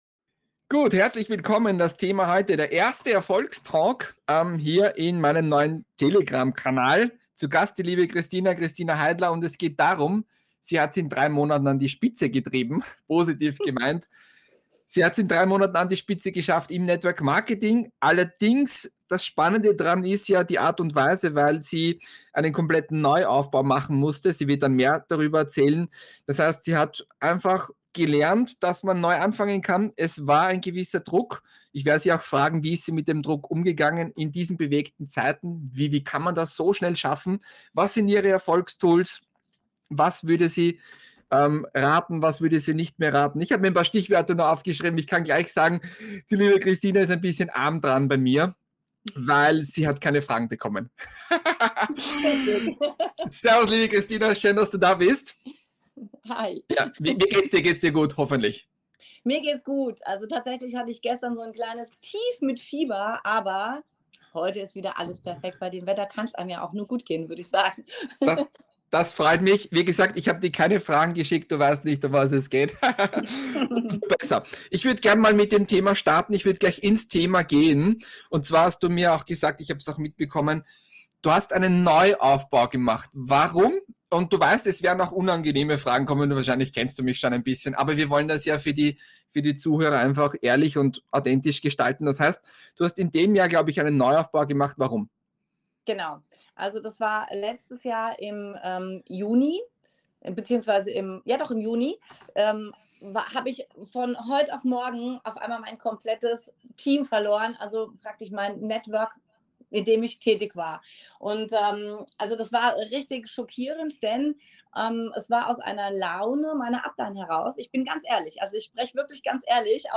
In diesem ehrlichen und ungeschnittenen Interview erfährst du ihre komplette Geschichte und ihre Strategien.